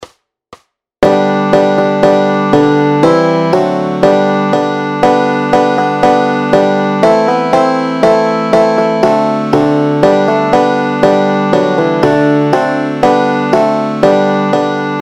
Aranžmá Noty, tabulatury na banjo
Formát Banjové album
Hudební žánr Lidovky